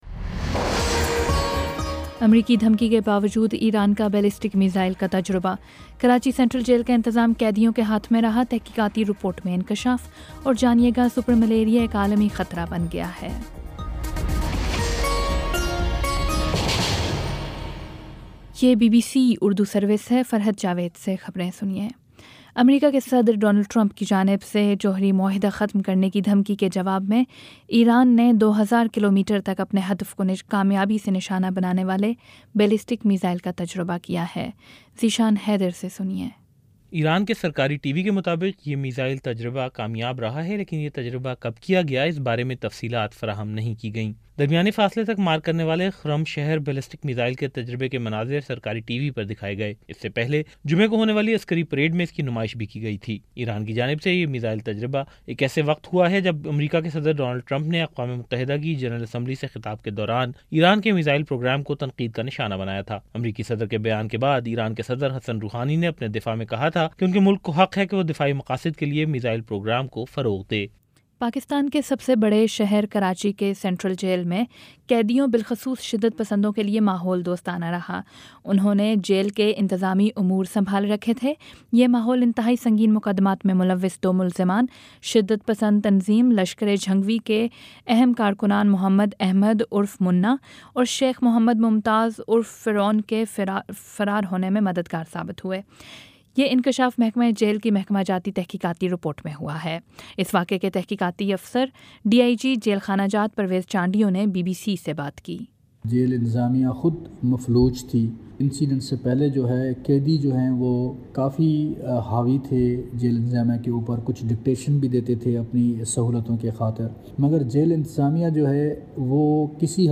ستمبر 23 : شام سات بجے کا نیوز بُلیٹن